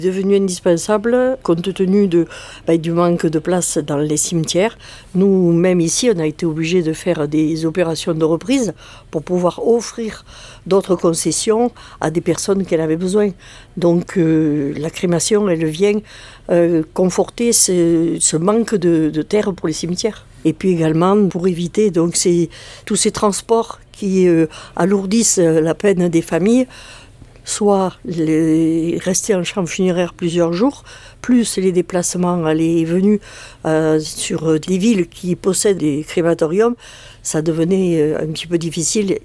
Une manière de marquer le coup pour une infrastructure attendue depuis longtemps par les Mendois et, plus largement, par les Lozériens, explique la maire de Mende, Régine Bourgade.